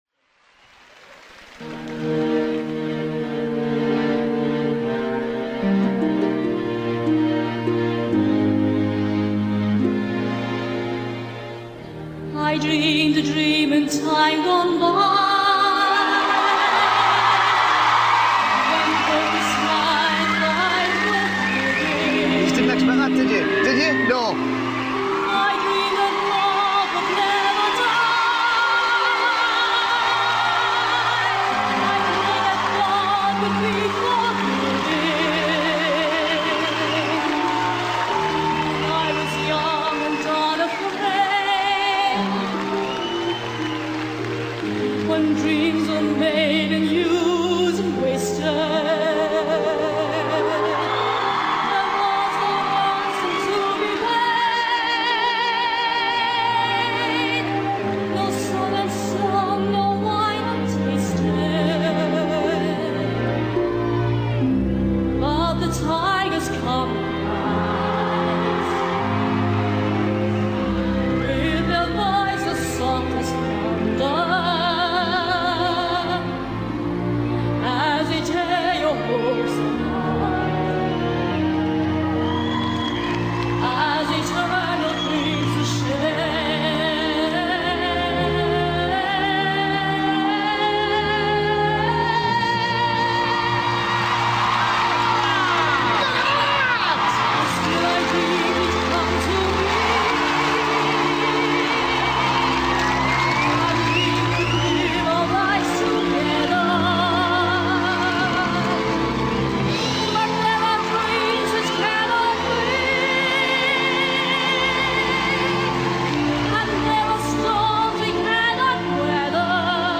现场除了掌声便是尖叫！
现场版：